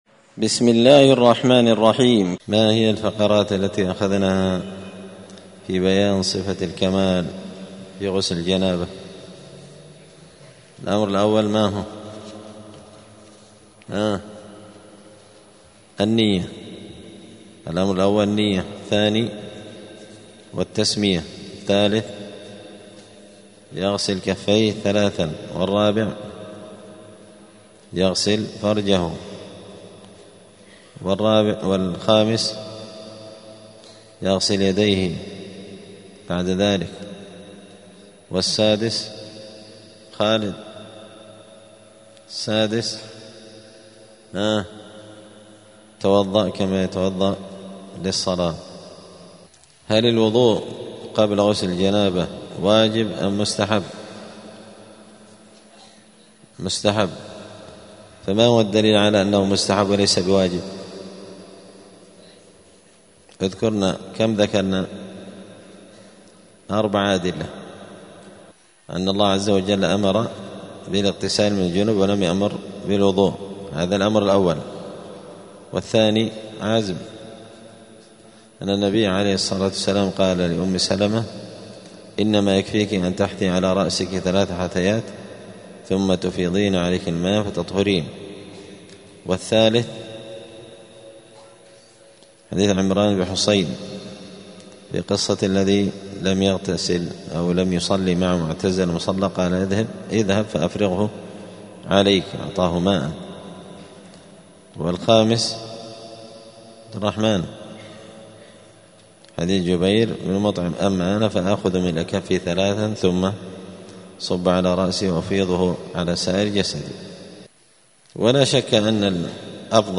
دار الحديث السلفية بمسجد الفرقان قشن المهرة اليمن
*الدرس الواحد والثمانون [81] {باب صفة الغسل حكم الوضوء في غسل الجنابة}*